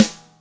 DrSnare31.WAV